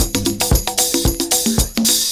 112PERCS04.wav